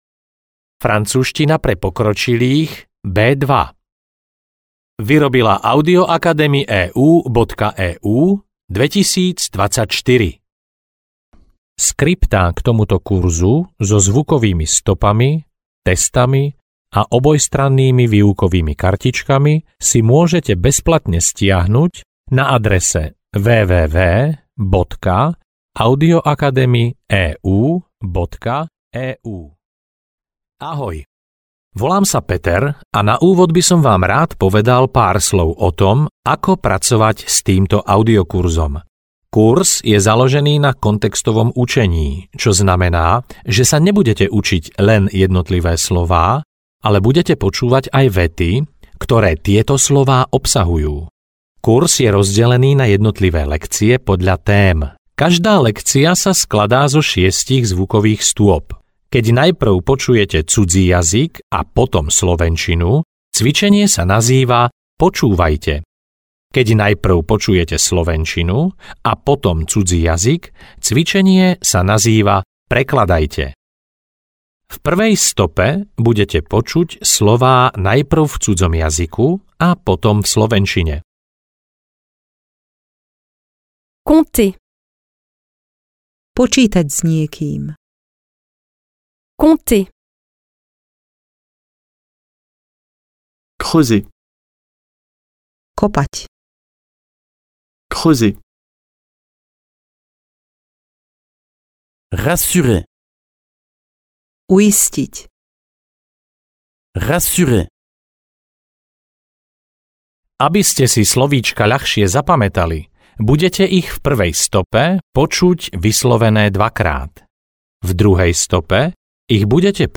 Audiokniha Francúzština pre pokročilých B2. Jednotlivé lekcie sú rozdelené podľa tém a každá obsahuje iný počet slov a viet.
Ukázka z knihy